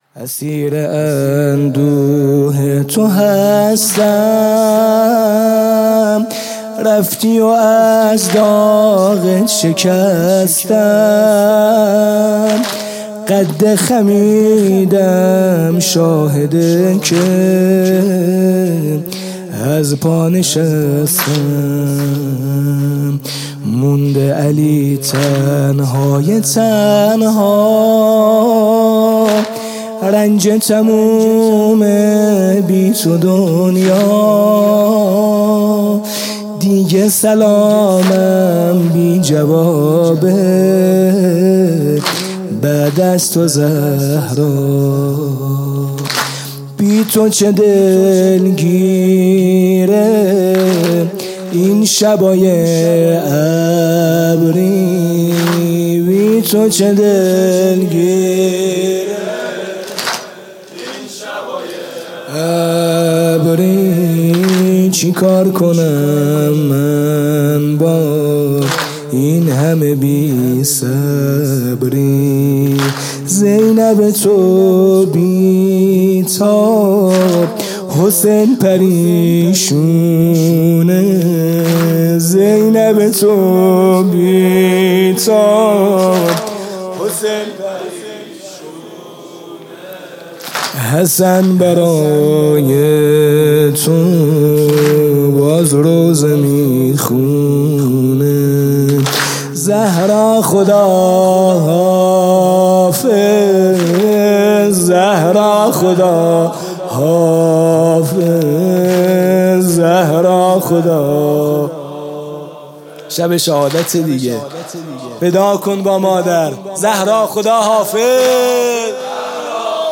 شب اول فاطمیه دوم ۱۴۰۴
music-icon تک: می‌ریزم هستیمو به راهت